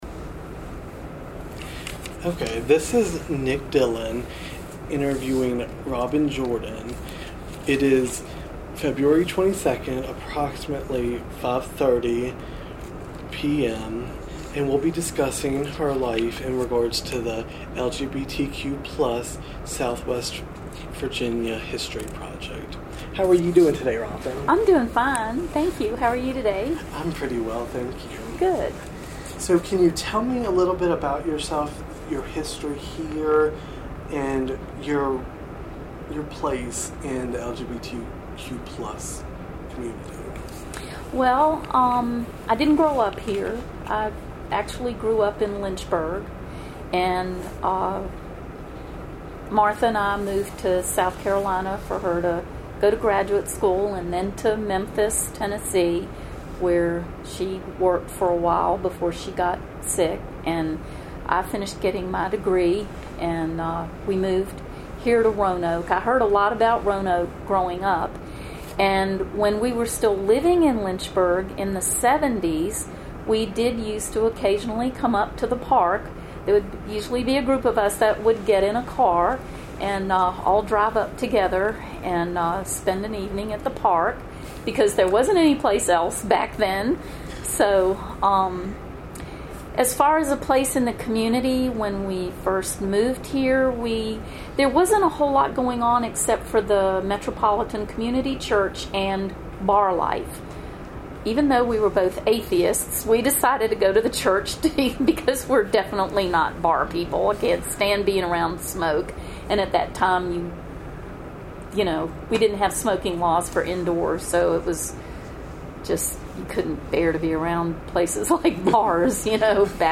Oral History Interview
Location: Fintel Library, Roanoke College